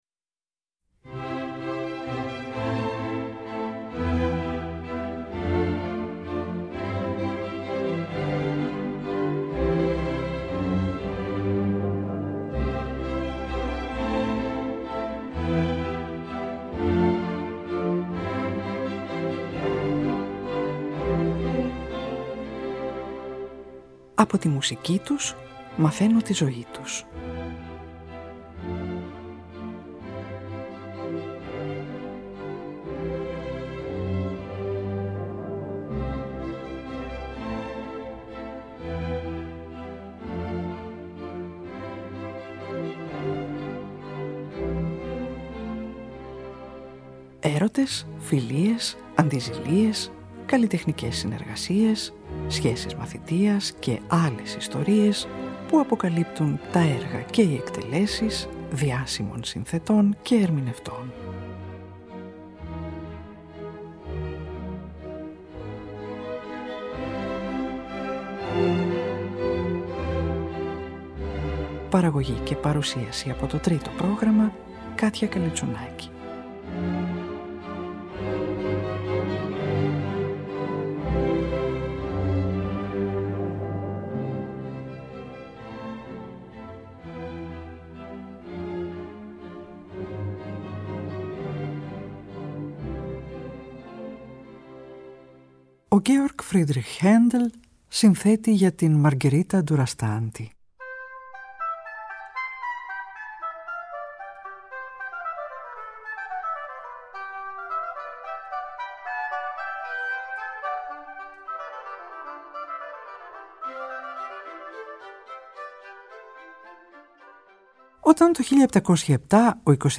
Αποσπάσματα από το ορατόριό του και από τις όπερες του
σοπράνο
κόντρατενόρος
μετζοσοπράνο